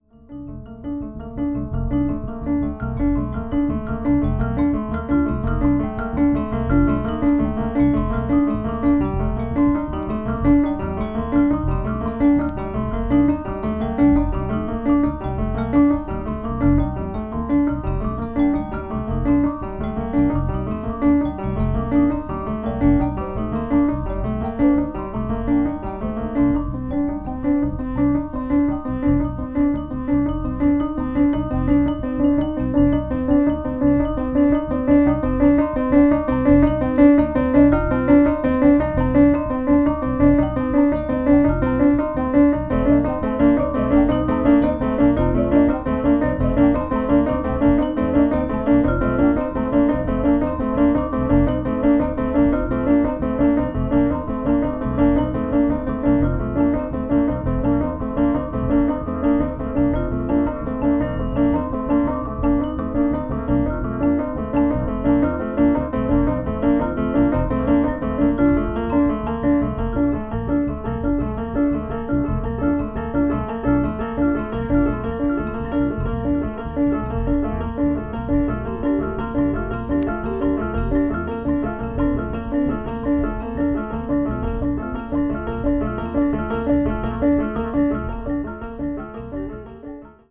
media : VG+/VG+(わずかにチリノイズが入る箇所あり,再生音に影響ない薄い擦れあり)